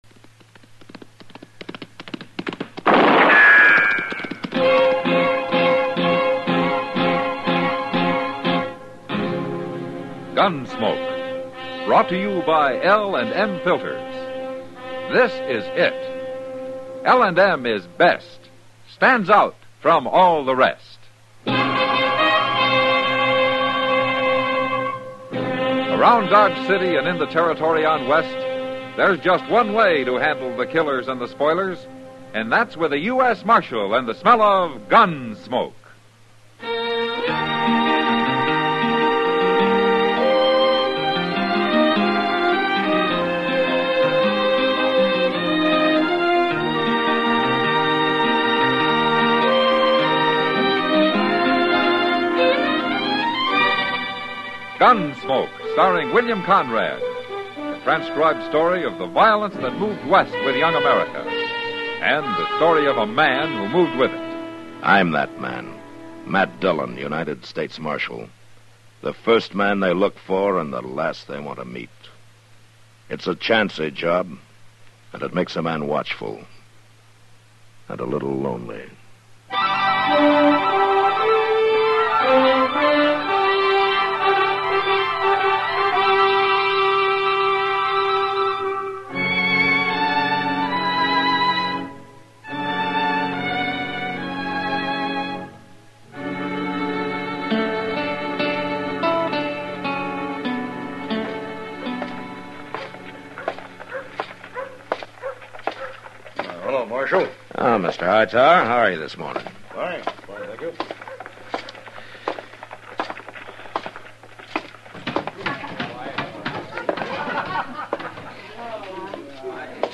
Gunsmoke is an American radio and television Western drama series created by director Norman Macdonnell and writer John Meston. The stories take place in and around Dodge City, Kansas, during the settlement of the American West. The central character is lawman Marshal Matt Dillon, played by William Conrad on radio and James Arness on television.